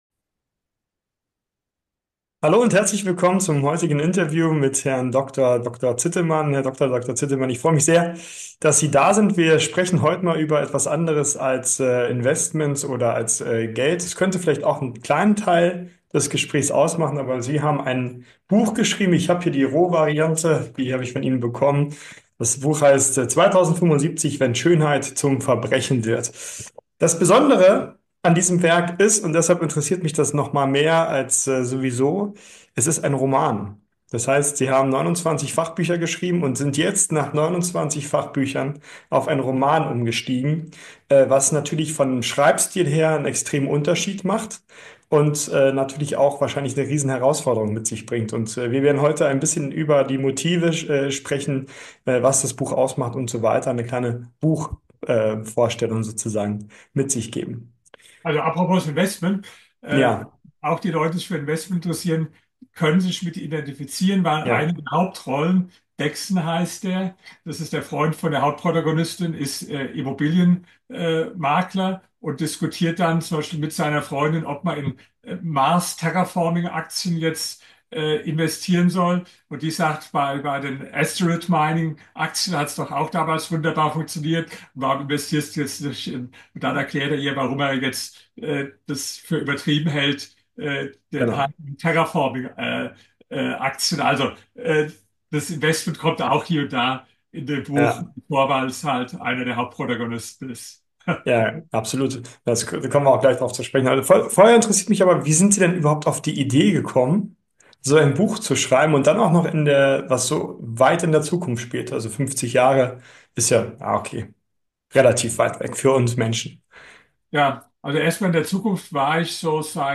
Interview über mein neues Buch "2075